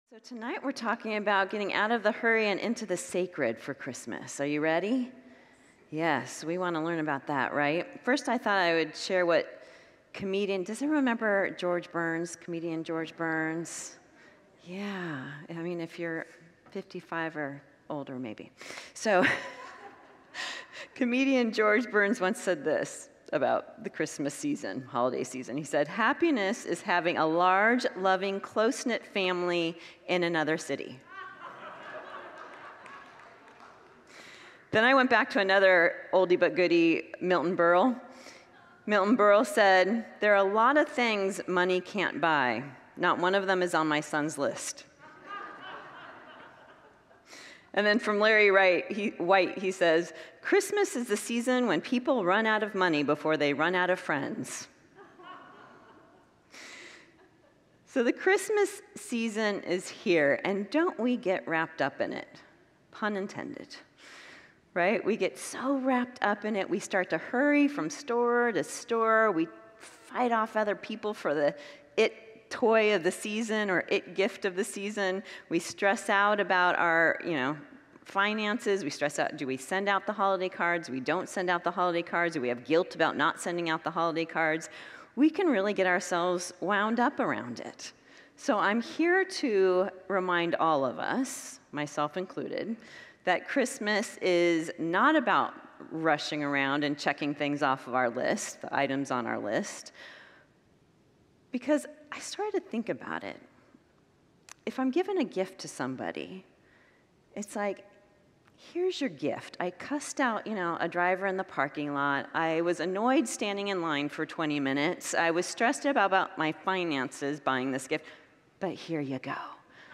Series: Wednesday Evening Worship
[Congregants laugh] So, comedian George Burns once said this about the Christmas season; holiday season.